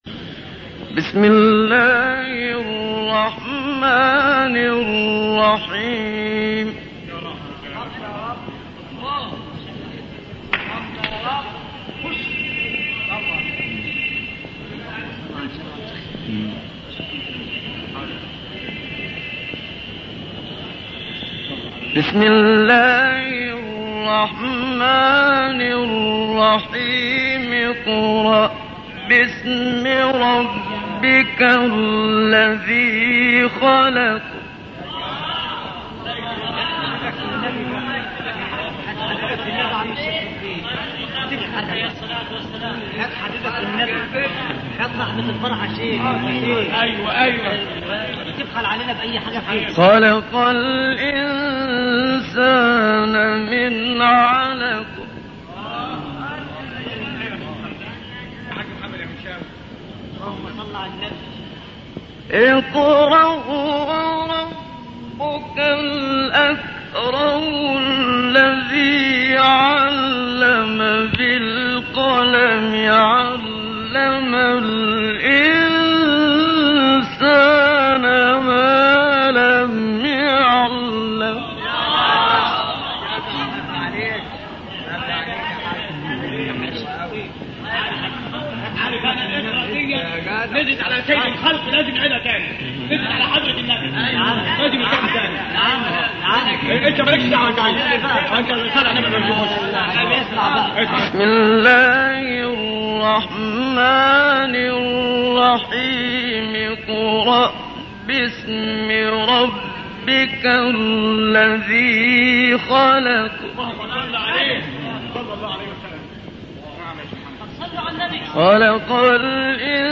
من روائع الشيخ محمد صديق المنشاوي سورة العلق من مسجد السيدة زينب